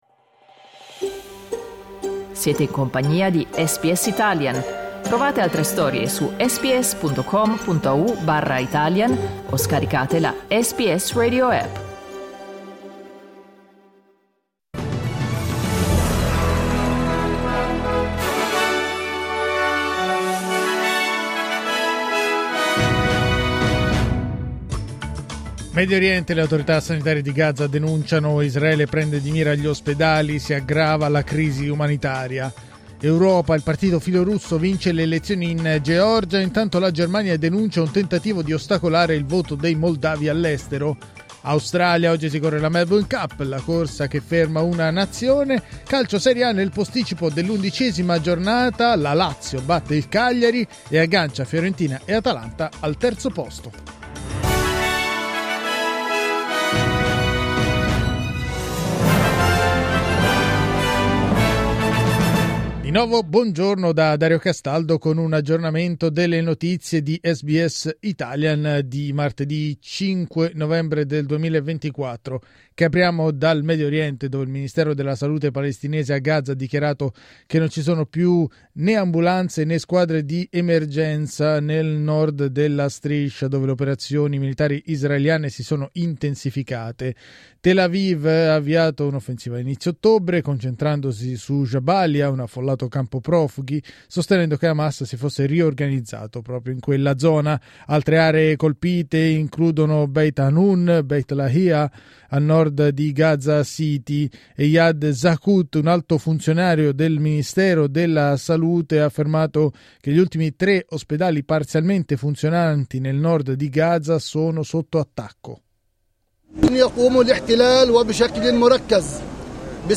News flash martedì 5 novembre 2024
L’aggiornamento delle notizie di SBS Italian.